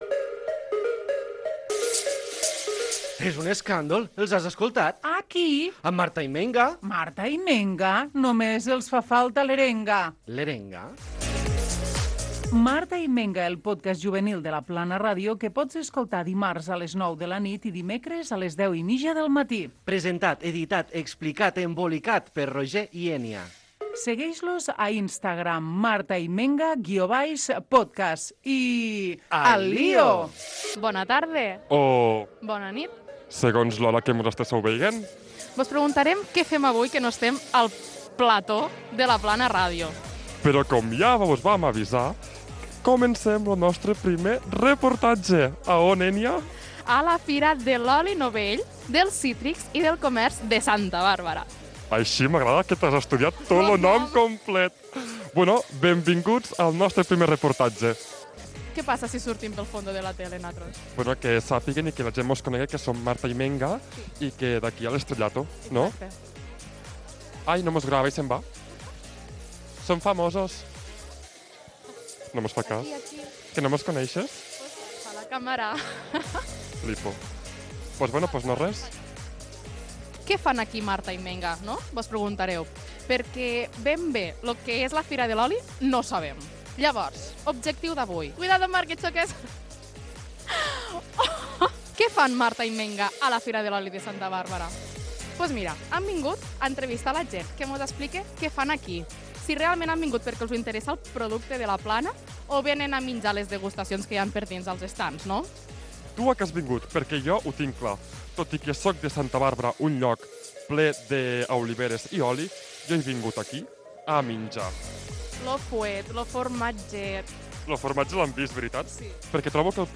Careta del programa, reportatge fet a la XXVII Fira de l'oli novell, dels cítrics i del comerç de Santa Bàrbara